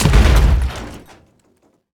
car-crash-1.ogg